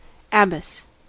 Ääntäminen
US : IPA : [ˈæ.bəs]